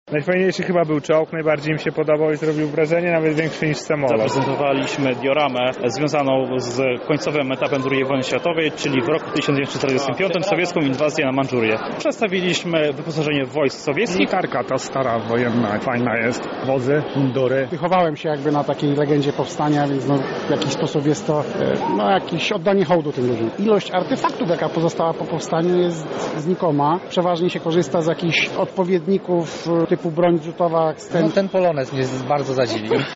Odbyła się wystawa historyczna „Militaria”.
Na wystawie obecny był nasz reporter